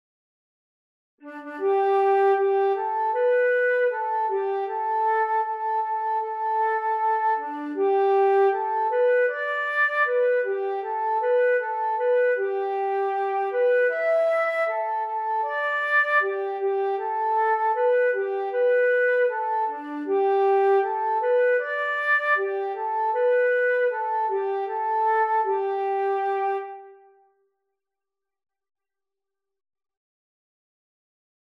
Morgenlied